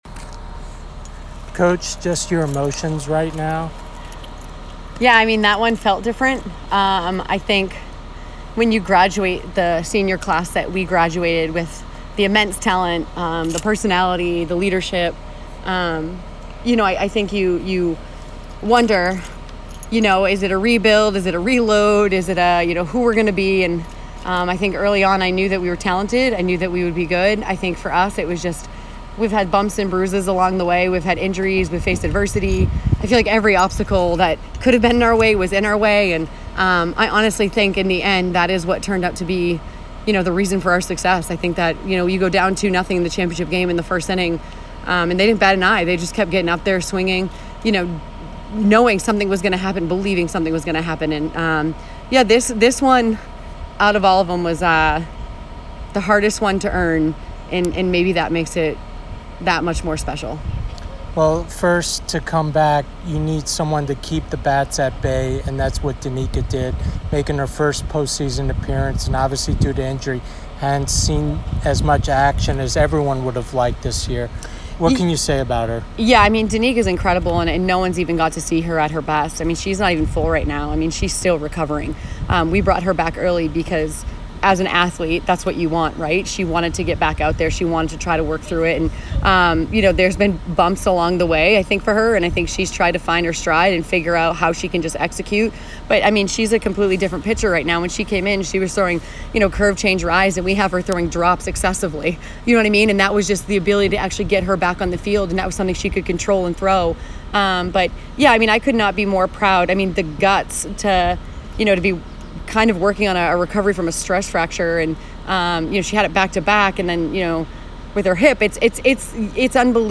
Softball / Patriot League Final Postgame Interview